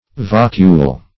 vocule - definition of vocule - synonyms, pronunciation, spelling from Free Dictionary
Search Result for " vocule" : The Collaborative International Dictionary of English v.0.48: Vocule \Voc"ule\, n. [L. vocula, dim. of vox, vocis, voice.]